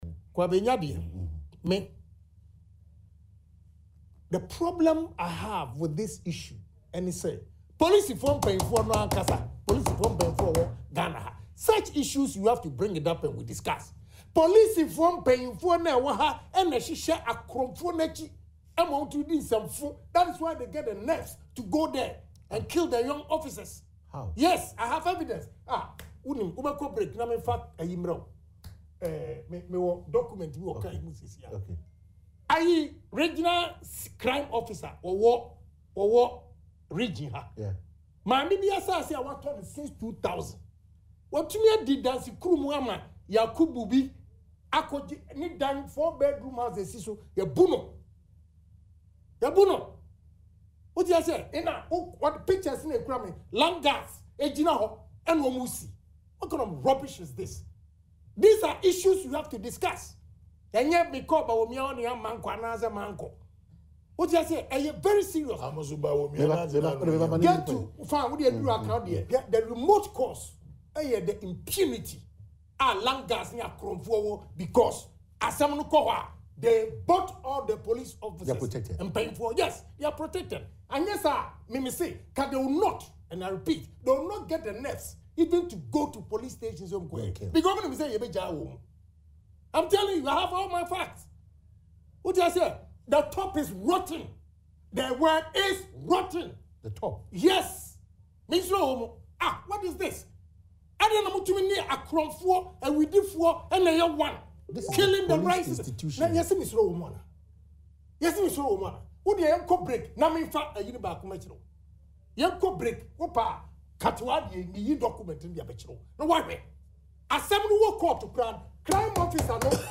The outspoken MP was speaking on Adom TV’s Morning Show ‘Badwam’ Tuesday, concerning recent attacks on security personnel across the country.